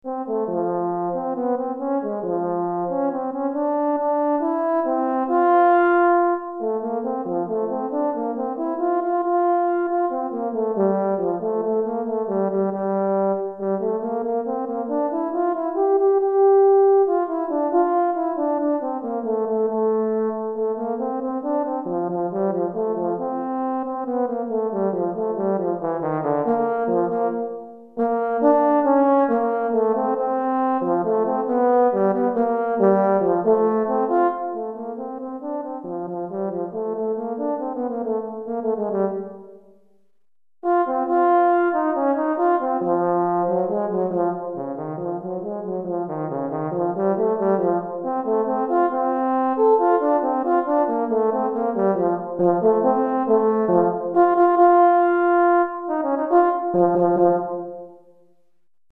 Etude pour Cor - Cor en Fa Solo